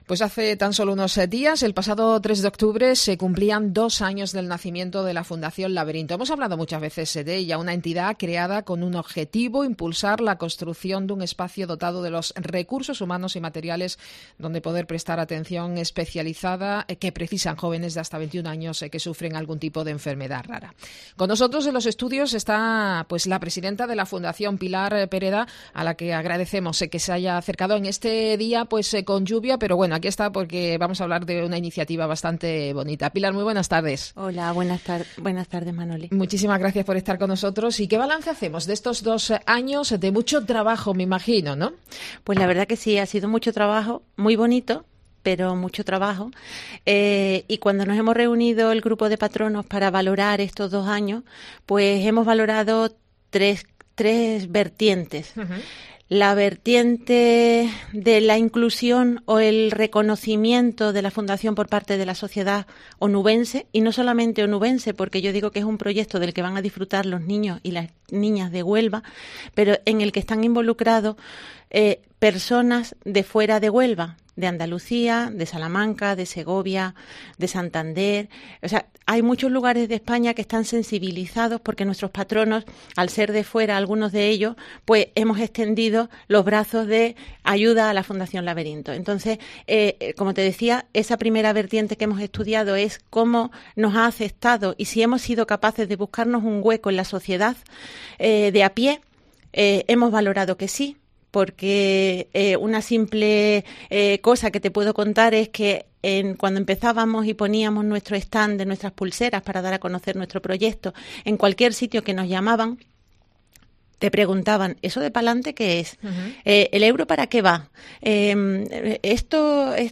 hoy se ha pasado por COPE